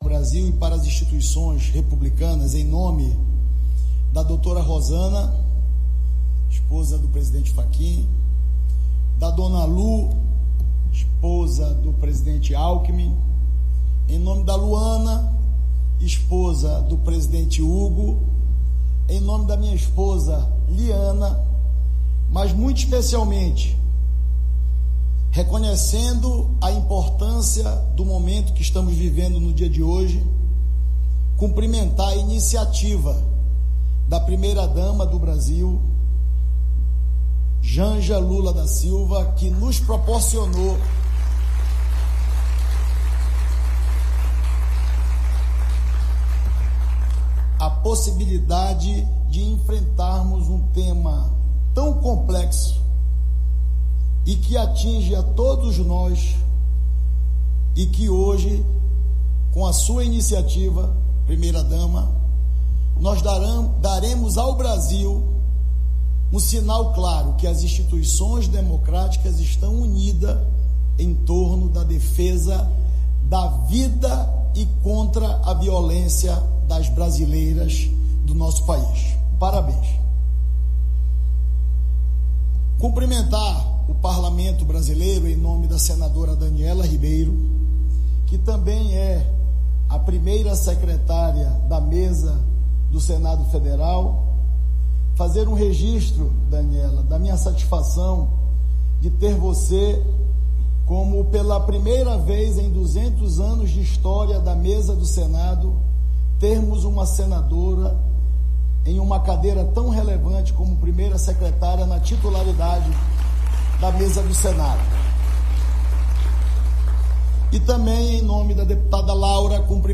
Confira na íntegra o discurso do presidente do Senado no ato de assinatura Pacto Nacional Brasil contra o Femi
Pronunciamento